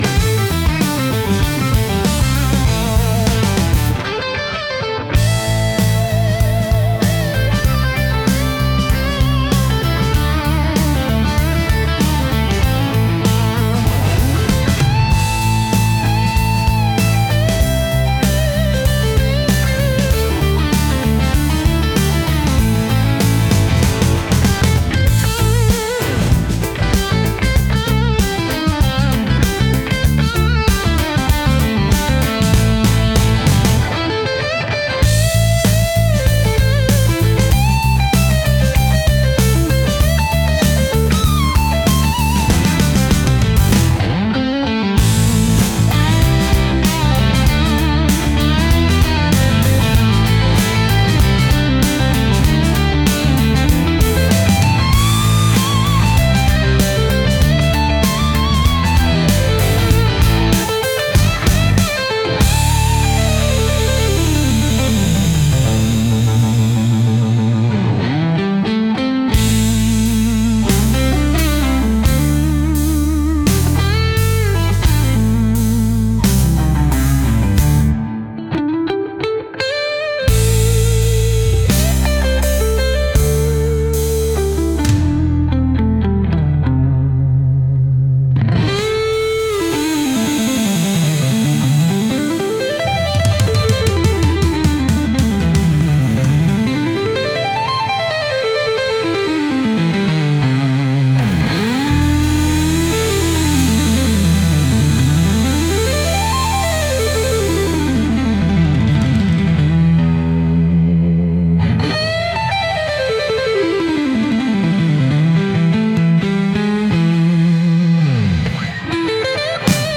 Instrumental - RLMradio Dot XYZ - 2.45.mp3